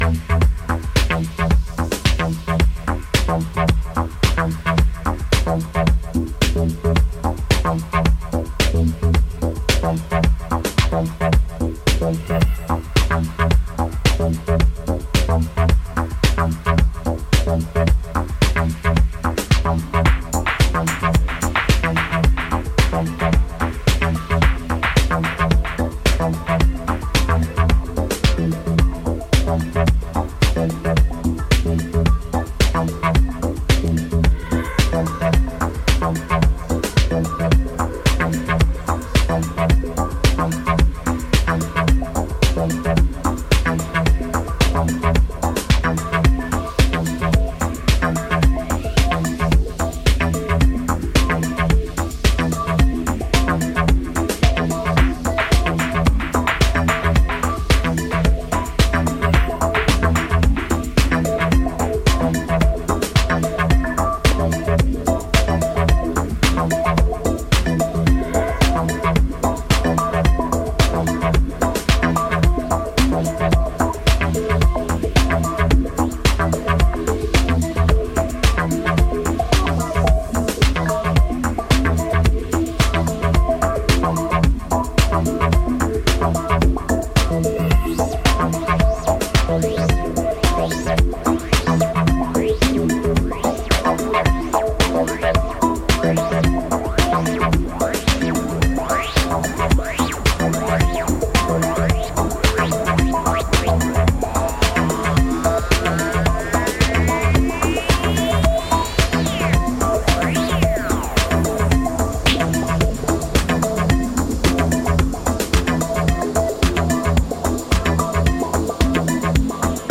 Ambient, Breakbeat
Leftfield
Techno